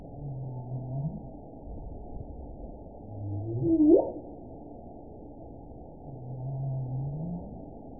event 917748 date 04/15/23 time 01:39:53 GMT (2 years, 1 month ago) score 8.81 location TSS-AB04 detected by nrw target species NRW annotations +NRW Spectrogram: Frequency (kHz) vs. Time (s) audio not available .wav